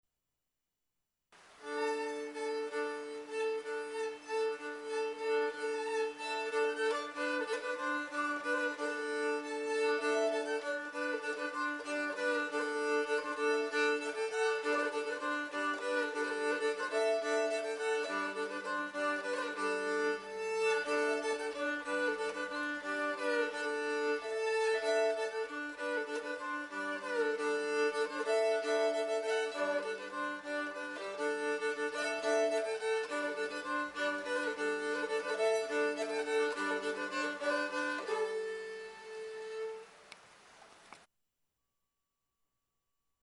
suka biłgorajska